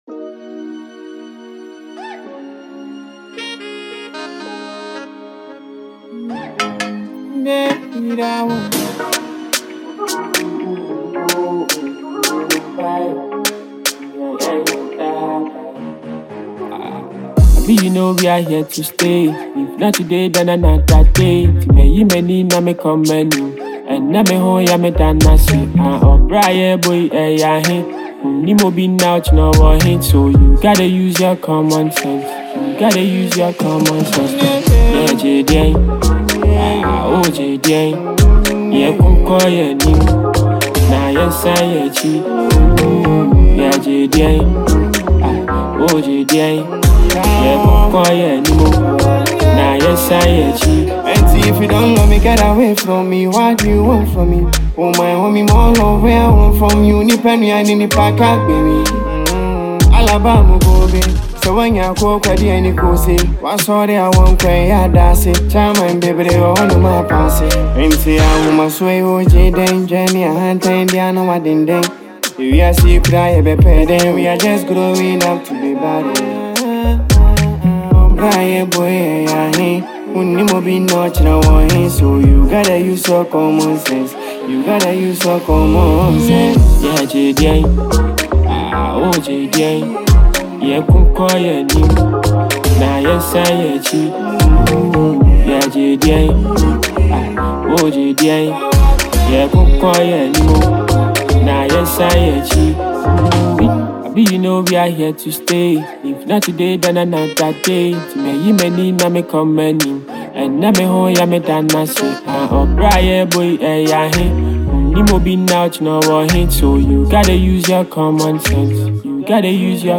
Talented Ghanaian singer and songwriter